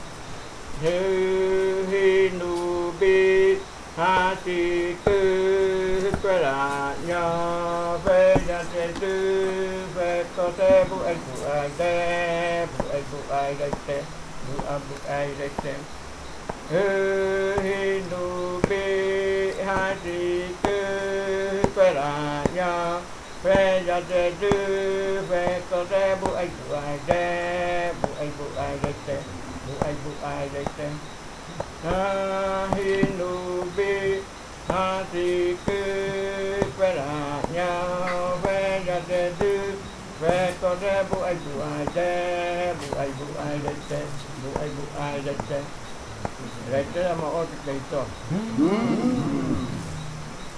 Leticia, Amazonas, (Colombia)
Grupo de danza Kaɨ Komuiya Uai
Canto fakariya de la variante Muruikɨ (cantos de la parte de arriba) Esta grabación hace parte de una colección resultante del trabajo de investigación propia del grupo de danza Kaɨ Komuiya Uai (Leticia) sobre flautas y cantos de fakariya.
Fakariya chant of the Muruikɨ variant (Upriver chants) This recording is part of a collection resulting from the Kaɨ Komuiya Uai (Leticia) dance group's own research on pan flutes and fakariya chants.